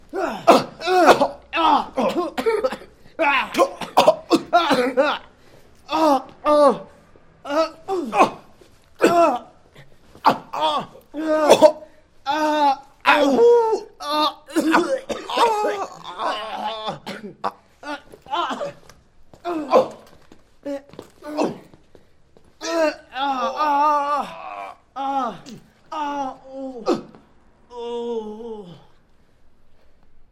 punch
描述：A single punch
标签： hurt ouch pain punch
声道立体声